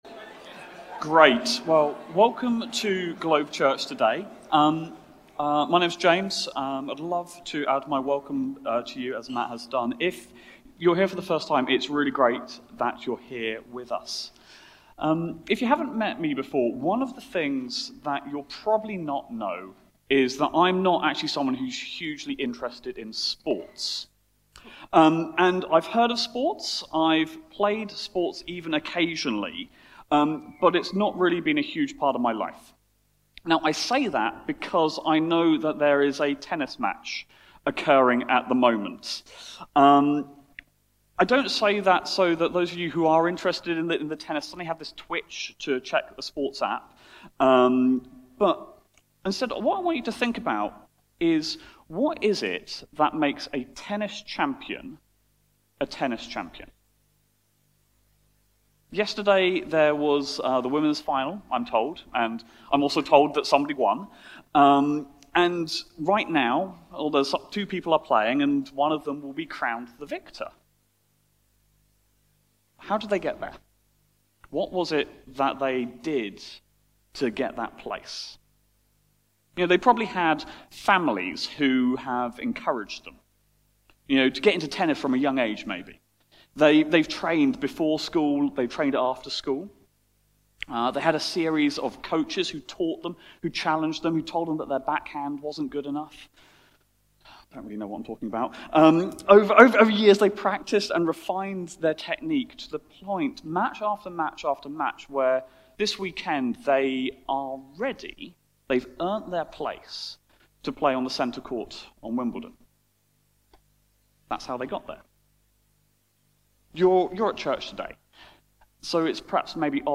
Sermon-Jul-13.mp3